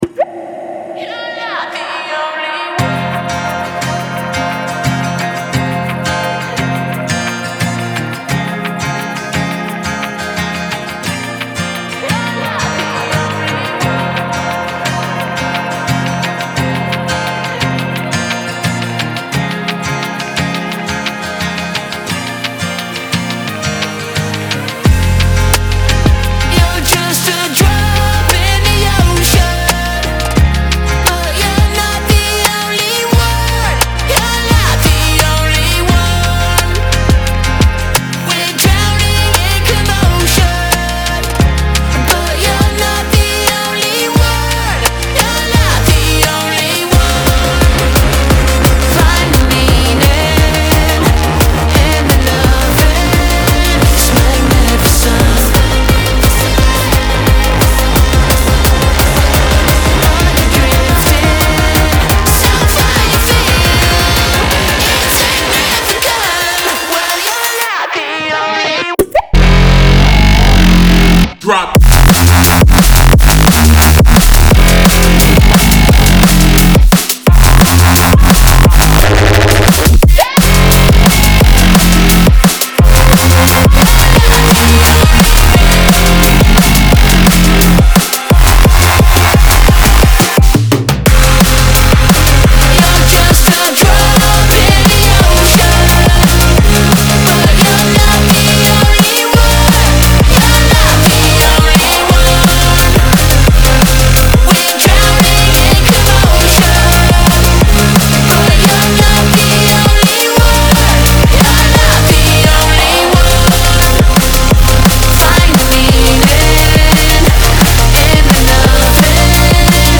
BPM174-174
Audio QualityPerfect (High Quality)
Drum and Bass song for StepMania, ITGmania, Project Outfox
Full Length Song (not arcade length cut)